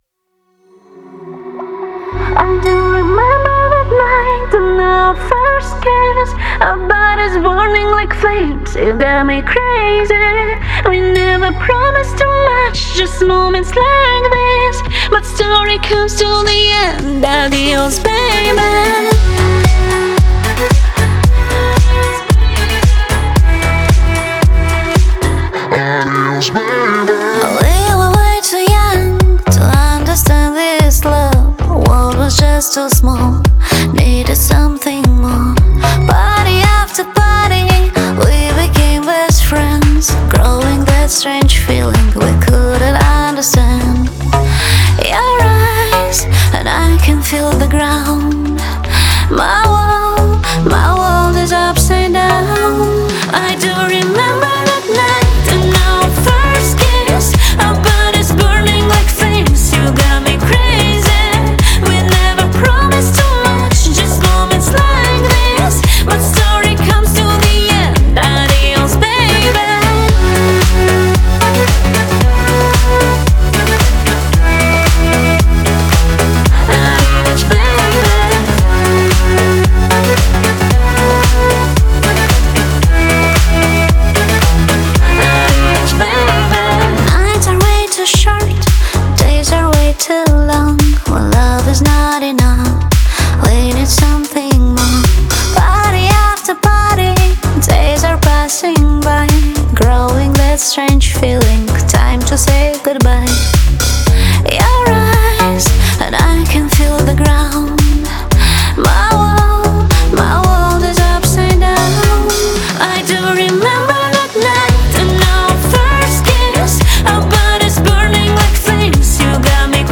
это зажигательная композиция в жанре дэнс-поп